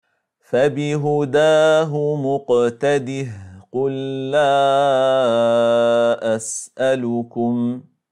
Enligt Ĥafş från ‘Aşim (في روايةِ حفصٍ عن عاصمٍ) läses det med en sukun vid både fortsättnig och stopp, som i: